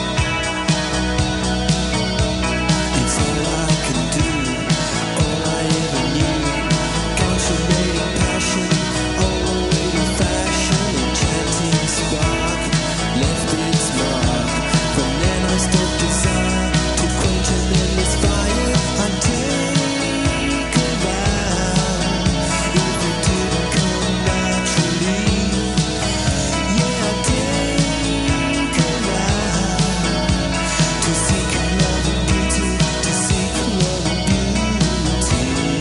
Recorded at La Maison (Bondi) except "At The Castle"